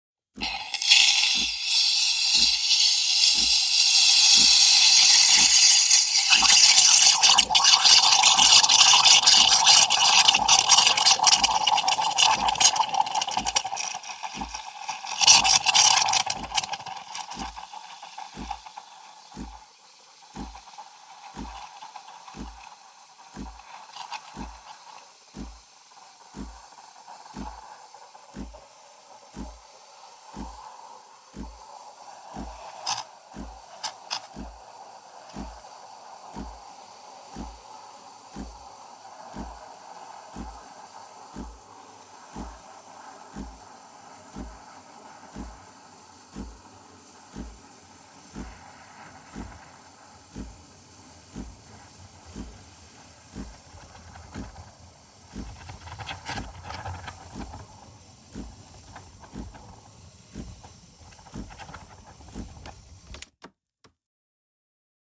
Espresso – Frothing Milk, Air Pressure, Fizzing
This track gives you the initial whisp and eventual whirl that characterizes a good milk carafe.
Systematic-Sound-Espresso-Frothing-Milk-Air-Pressure-Fizzing.mp3